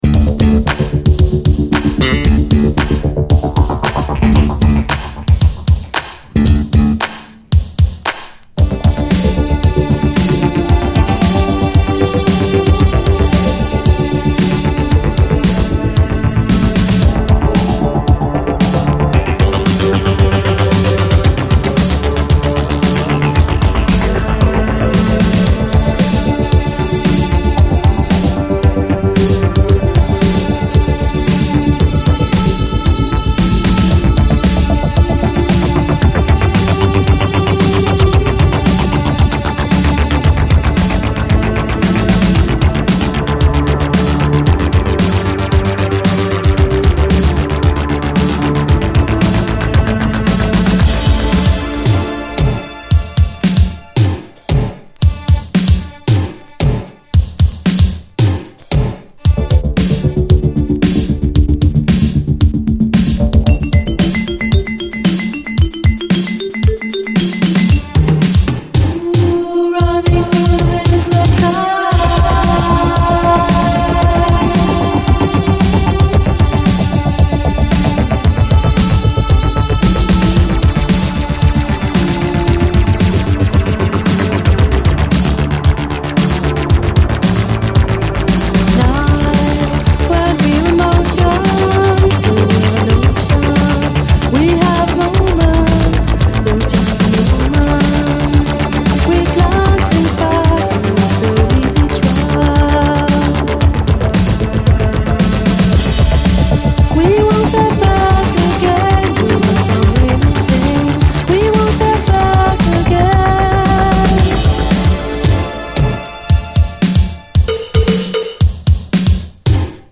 ラテン・ロック
ズッシリと重いファンク